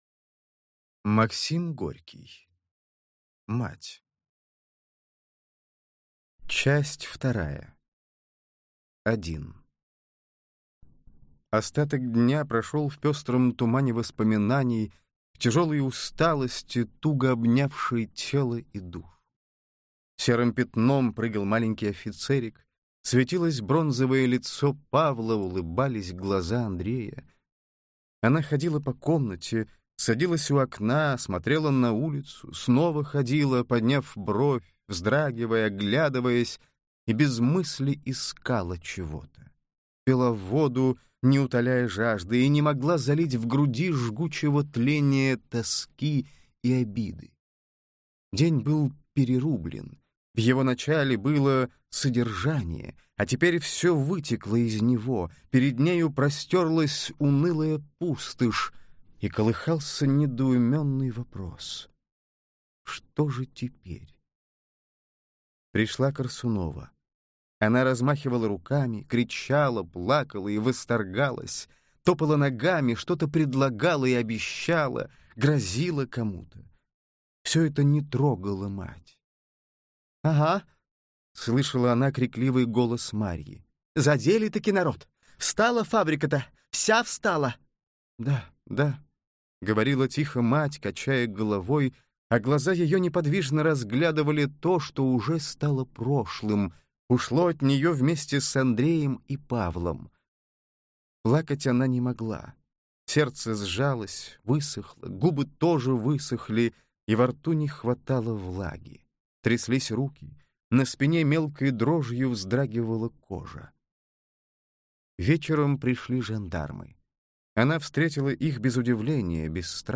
Аудиокнига Мать. Часть 2 | Библиотека аудиокниг